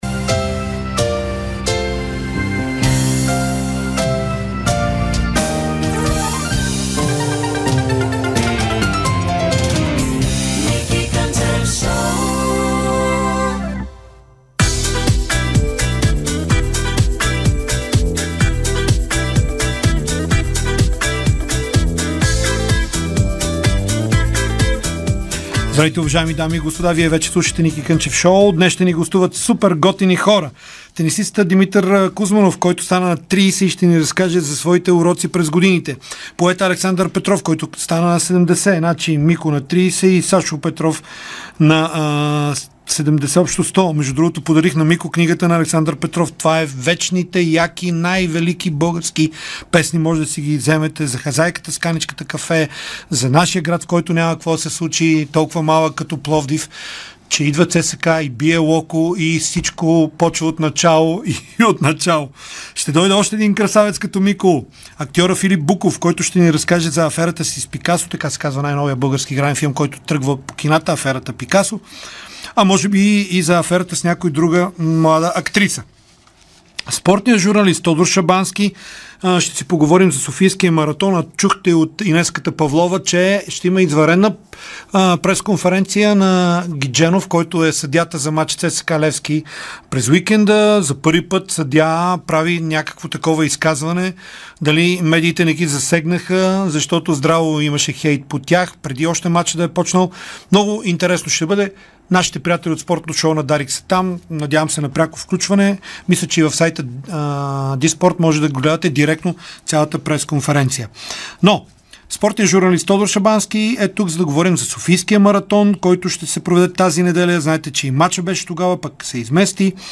Димитър Кузманов бе специален гост на Ники Кънчев в неговото шоу по Дарик радио. Българският тенисист разкри интересни подробности за календара си, като само през този сезон е изиграл над 30 турнира.
Чуйте цялото интервю на Ники Кънчев с Димитър Кузманов в приложения звуков файл!